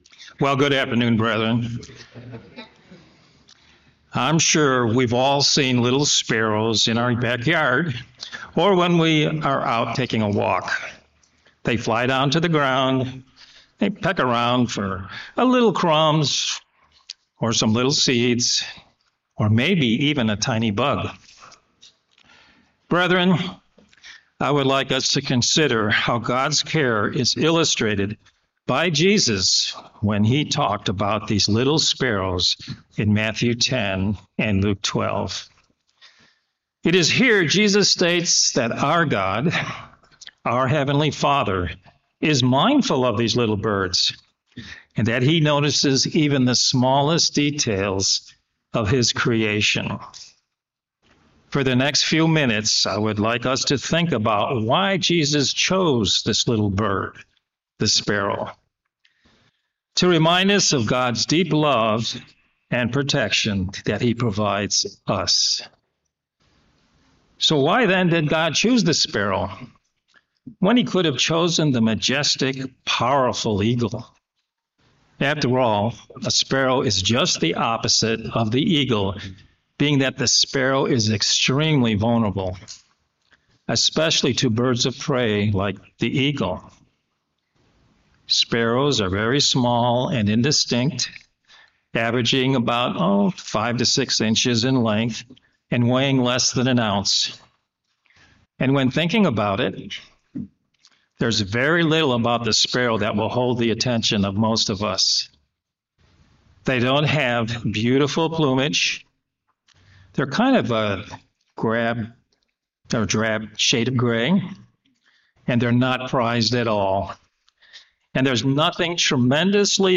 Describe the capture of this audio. Given in Aransas Pass, Texas